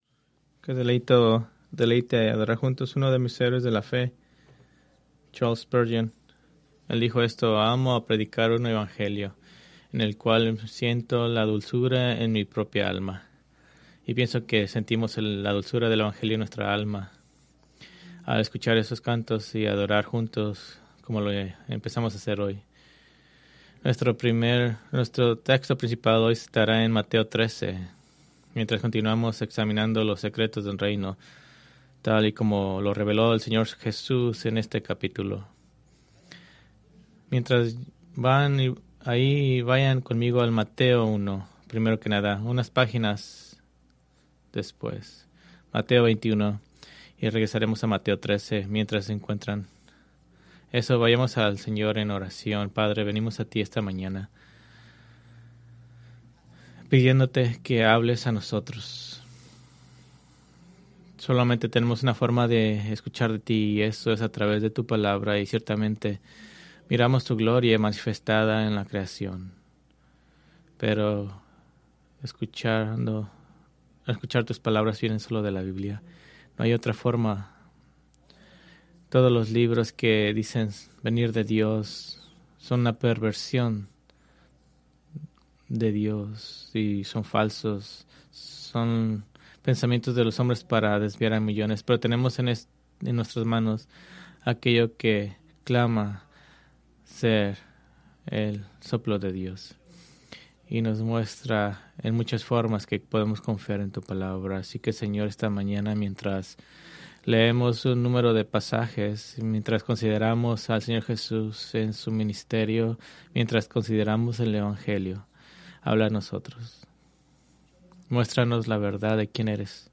Preached March 29, 2026 from Mateo 13:47-50